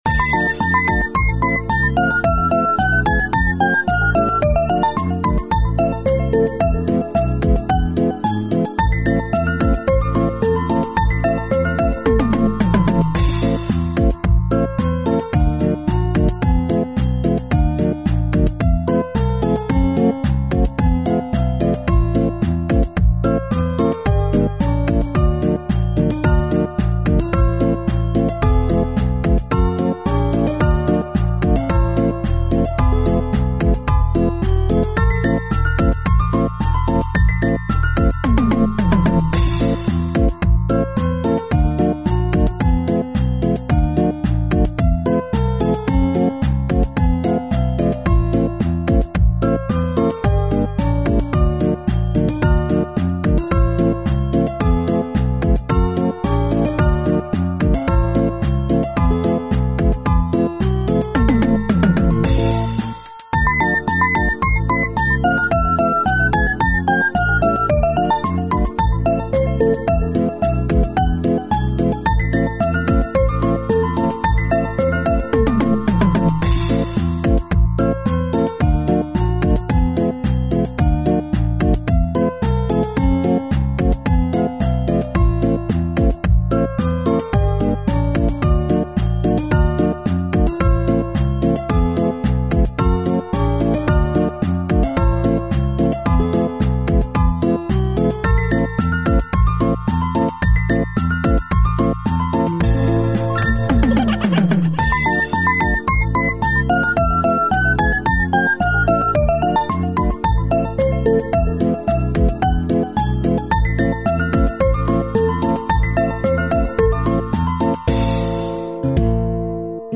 минус